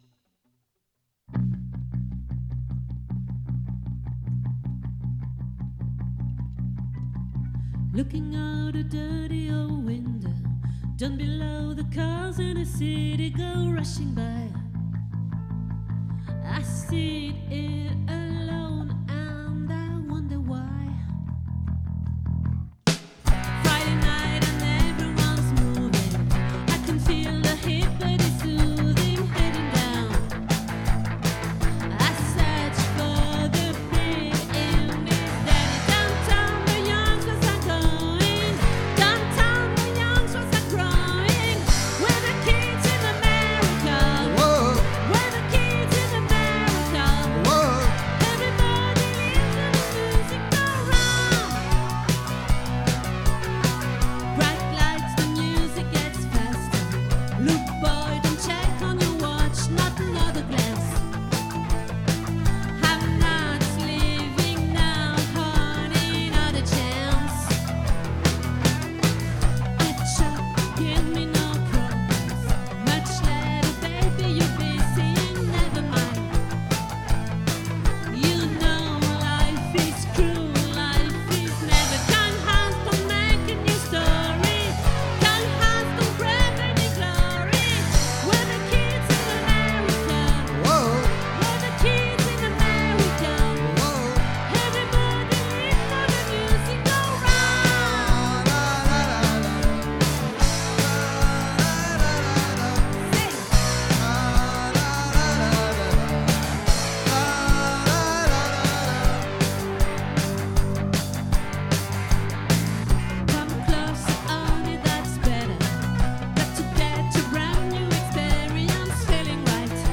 🏠 Accueil Repetitions Records_2025_09_01